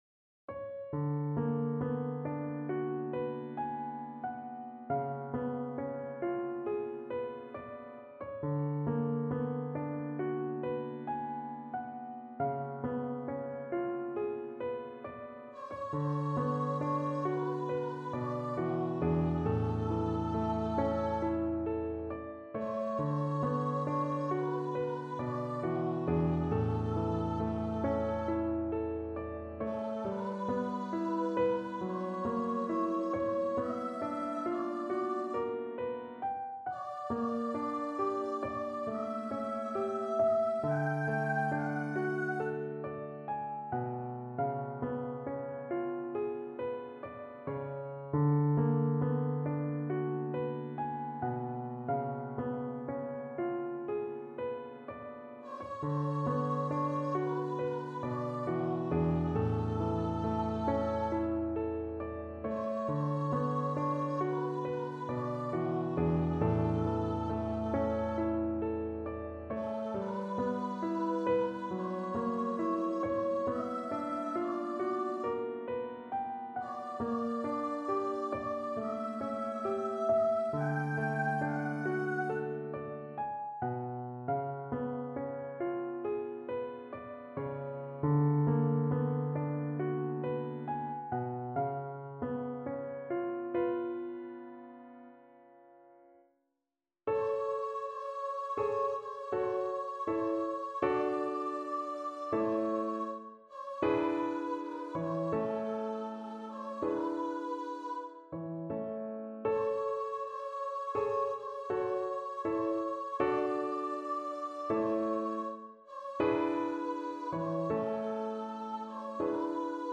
Schumann, Robert - Dichterliebe, Op.48 (High Voice) (Complete) Free Sheet music for Voice
Instrument: Voice
Style: Classical